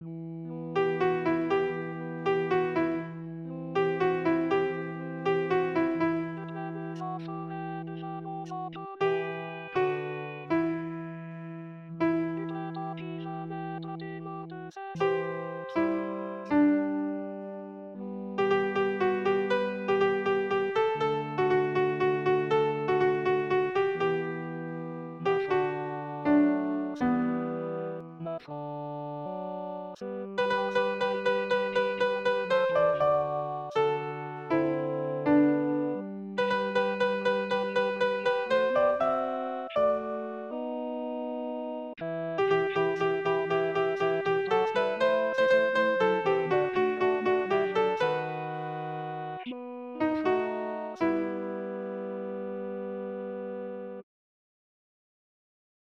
Soprano (.mp3) Alto (.mp3)